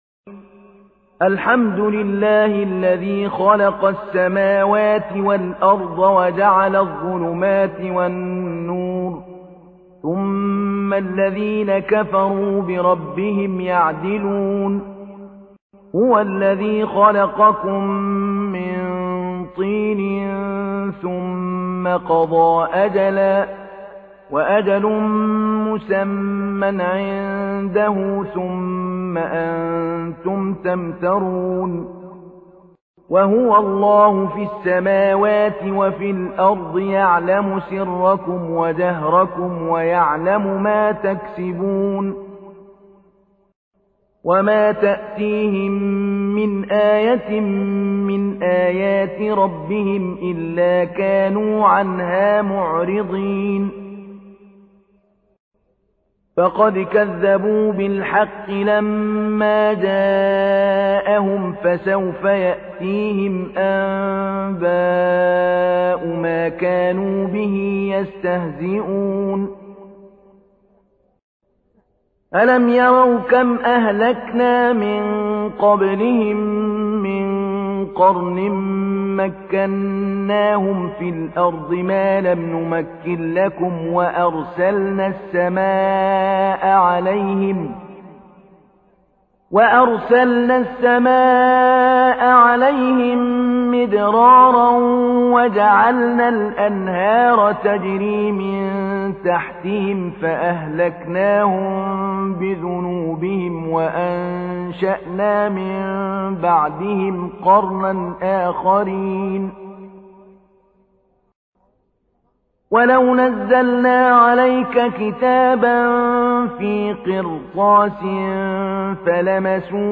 تحميل : 6. سورة الأنعام / القارئ محمود علي البنا / القرآن الكريم / موقع يا حسين